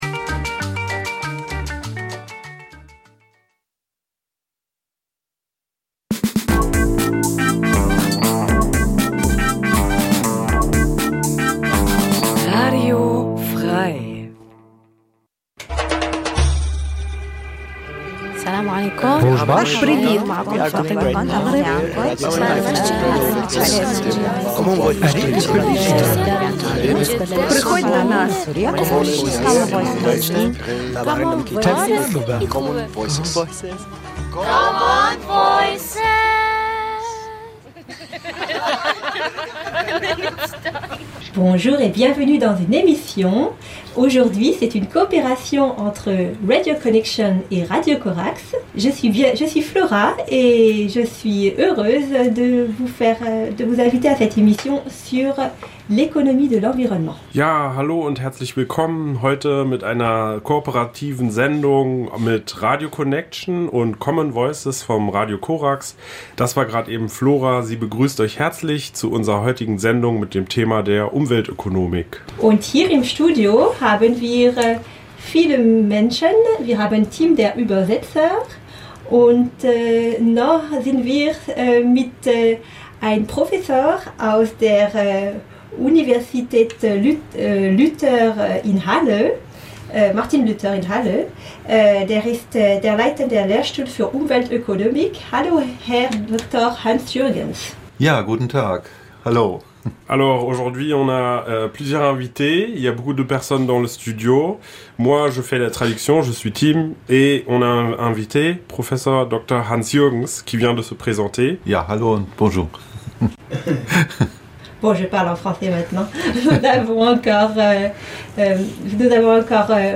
Seit 2016 gibt es bei unserem Schwesternradio Radio Corax die mehrsprachige Sendung Common Voices. Es ist eine Sendung von Gefl�chteten und MigrantInnen in Halle und Umgebung.